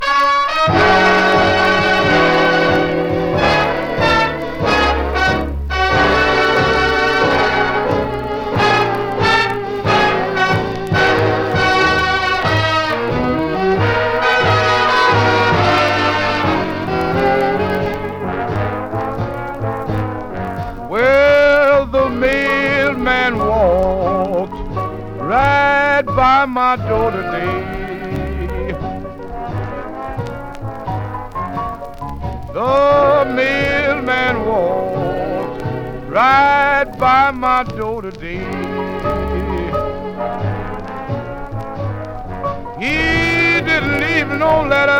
Jazz, Blues, Jump Blues　USA　12inchレコード　33rpm　Mono